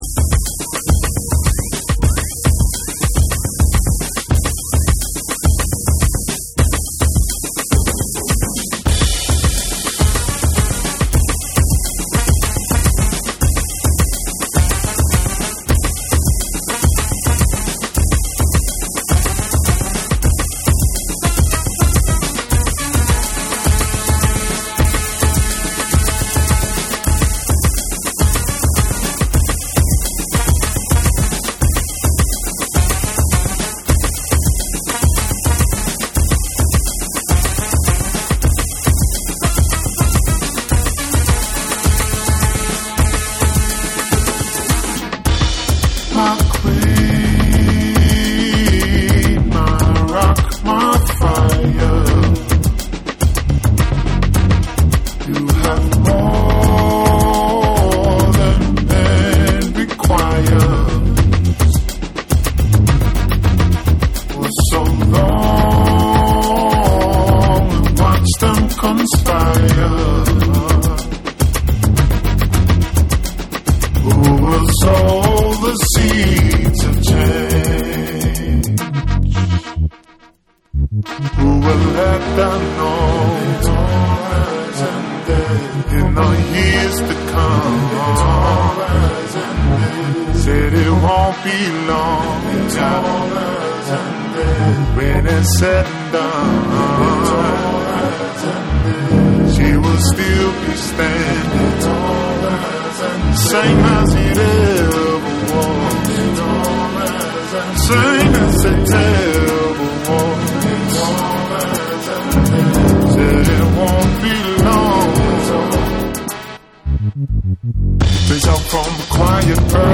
ニュージーランドで活動するレゲエ・バンド
細かく刻まれるアフロ調のリズムを軸に、ホーンや鍵盤のメロディーが絡み、病み付き必至のブルージーなヴォーカルが展開。
TECHNO & HOUSE / ORGANIC GROOVE / DETROIT